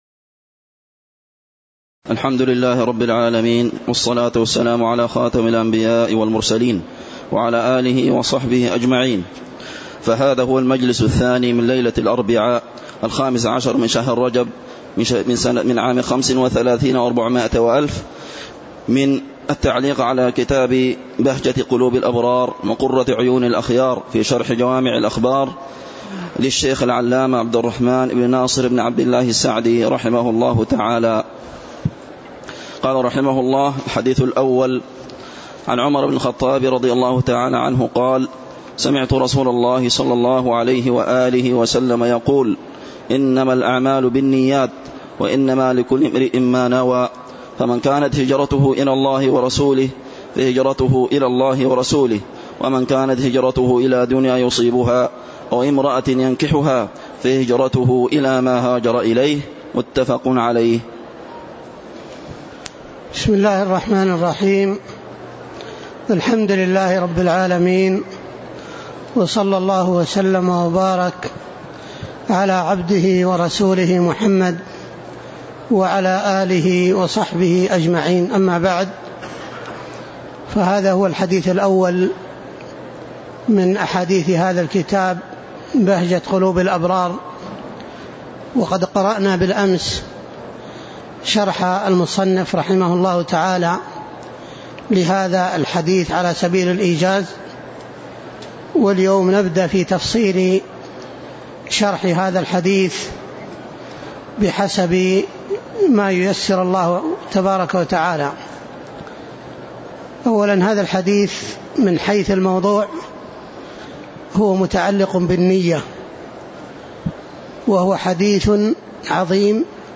تاريخ النشر ١٥ رجب ١٤٣٥ المكان: المسجد النبوي الشيخ